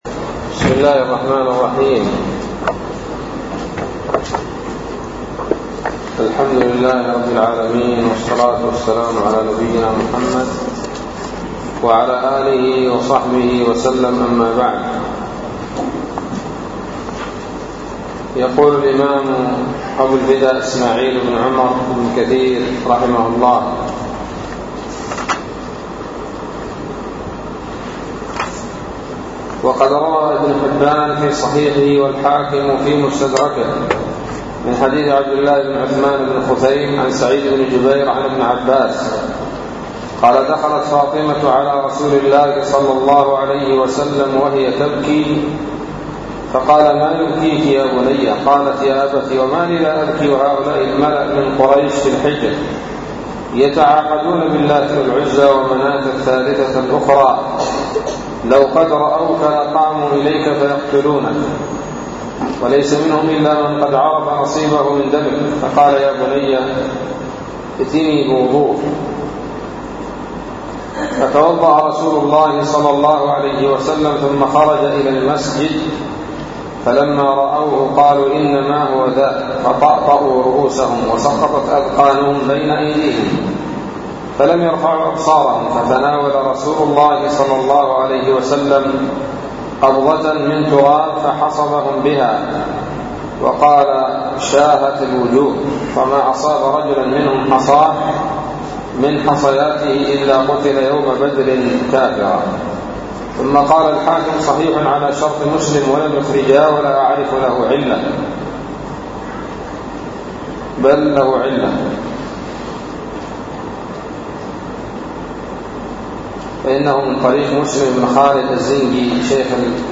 الدرس السابع عشر من سورة الأنفال من تفسير ابن كثير رحمه الله تعالى